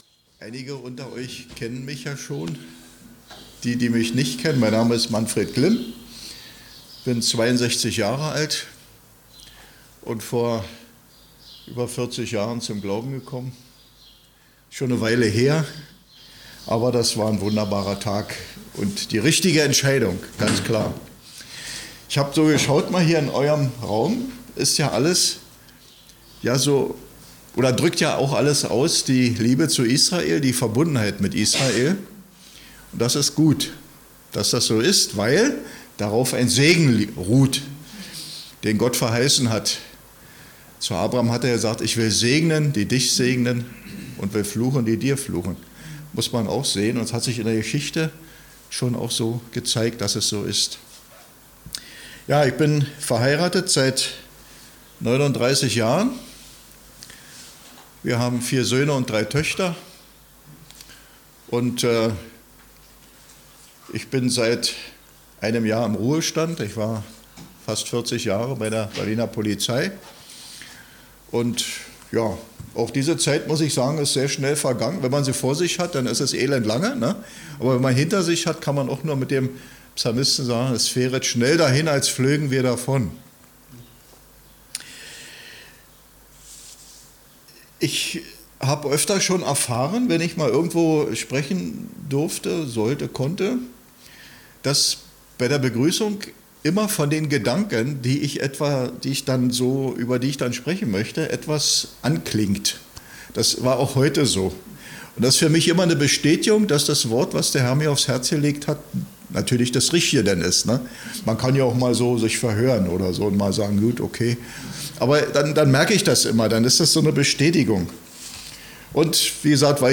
Open-Air-Lobpreiskonzert mit Picknick und Musikern aus verschiedenen Gemeinden der Stadt:
Open-Air-Worship im Wiesenpark, (zwischen Eisenacher Straße und Landsberger Allee), nahe zum Jugendklub „Anna Landsberger“